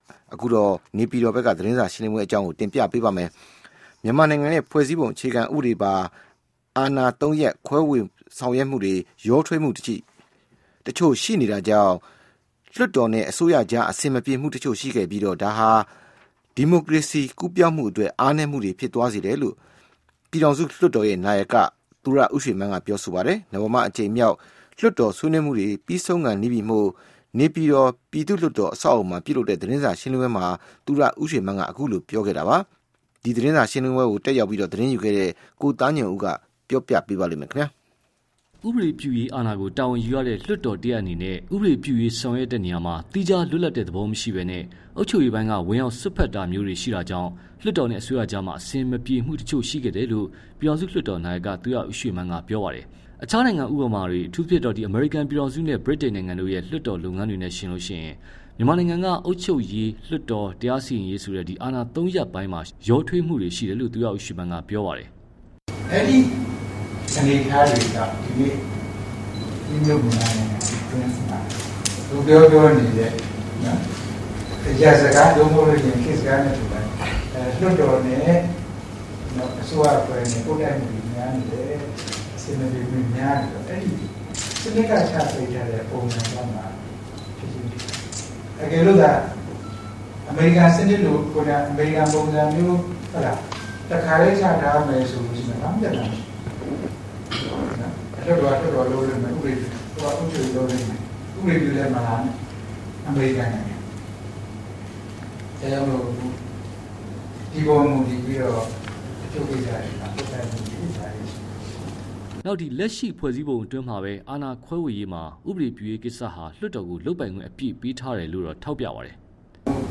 သူရဦးရွှေမန်း သတင်းစာရှင်းလင်းပွဲ